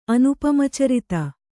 ♪ anupamacaritra